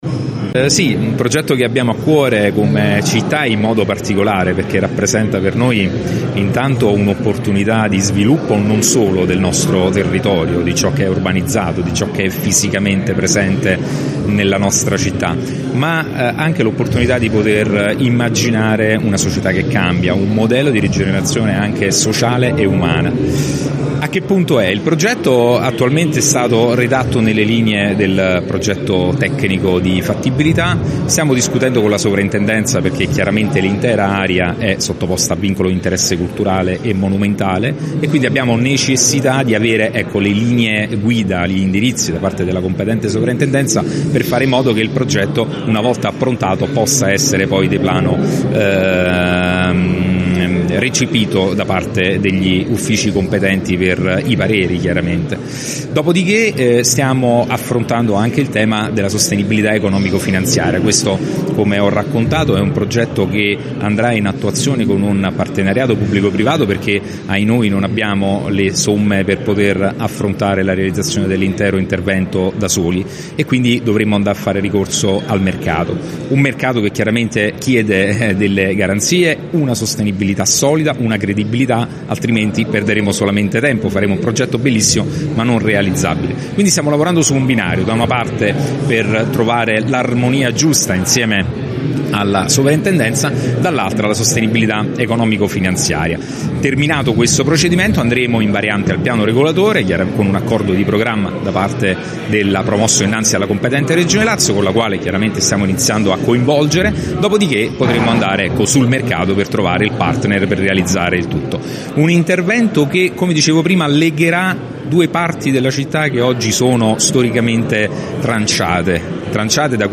Il sindaco di Gaeta Cristian Leccese al microfono di Gr Latina ha fatto il punto sullo stato del progetto Ex Avir nato per ricucire la città